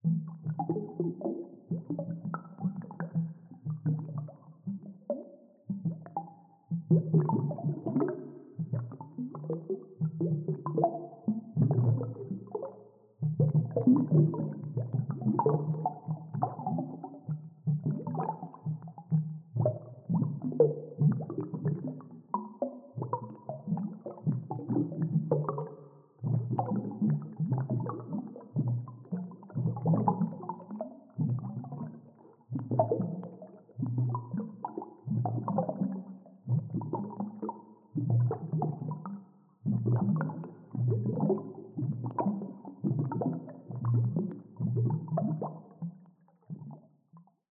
Dive Deep - Small Bubbles 03.wav